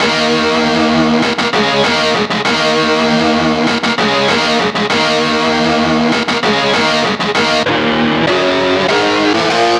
Rock Star - Power Guitar 05.wav